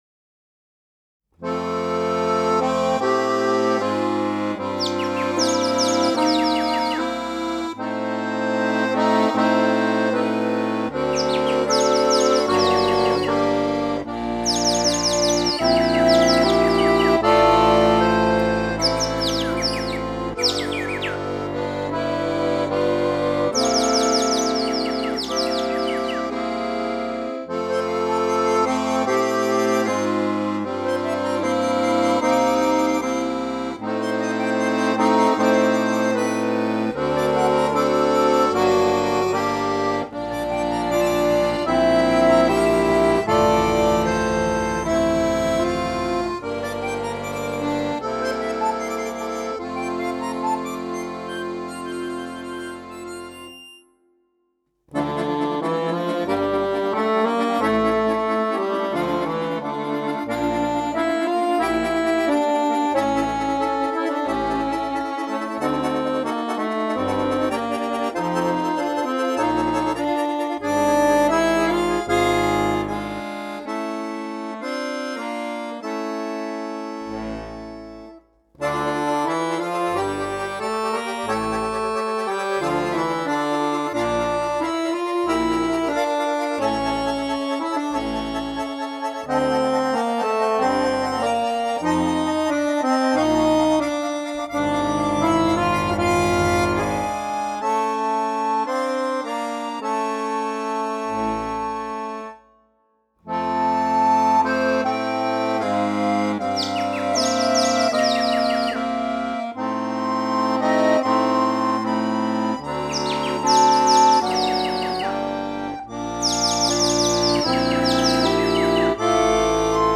for accordion quintet or larger ensemble